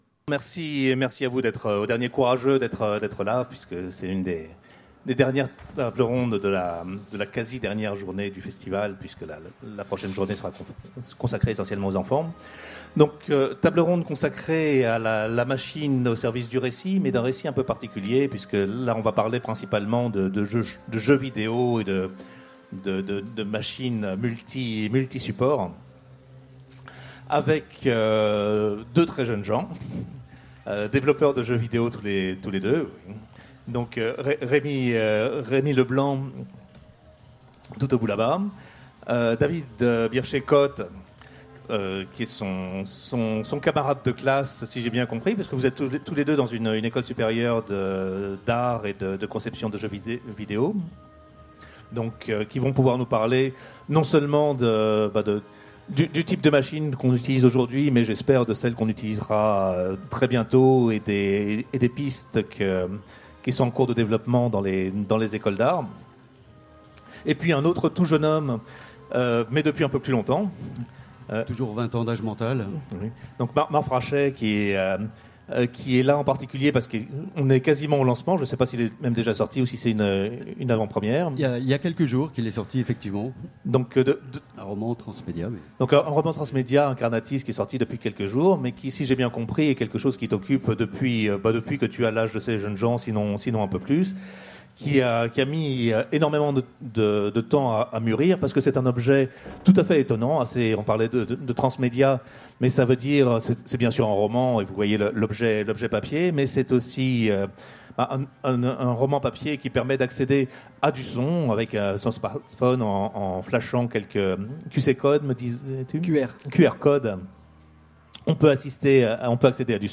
Utopiales 2016 : Conférence La machine au service du récit